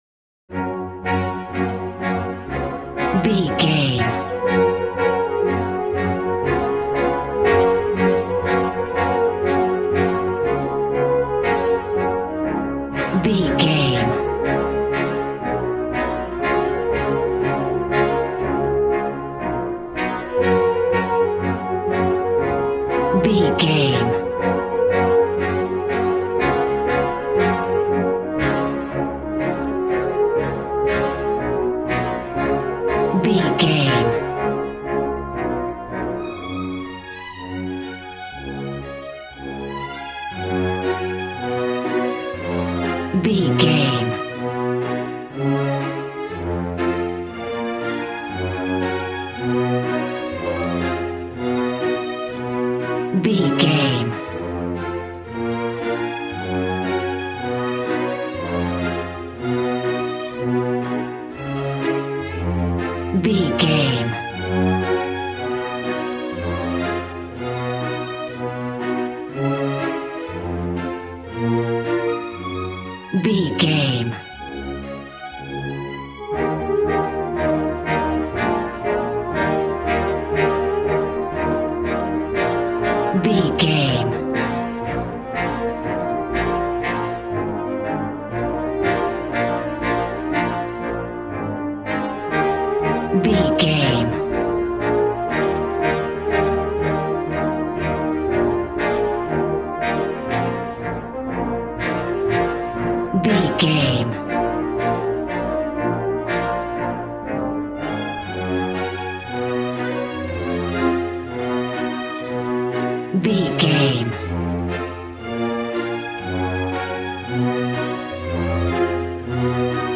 Aeolian/Minor
B♭
cheerful/happy
joyful
drums
acoustic guitar